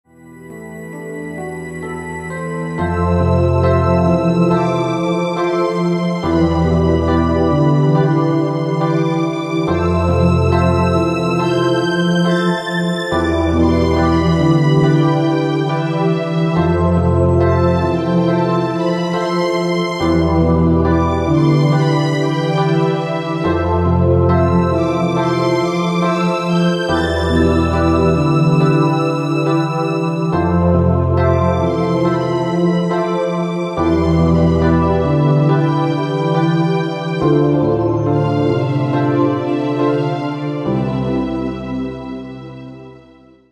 • Качество: 192, Stereo
грустные
спокойные
без слов
инструментальные
Melodic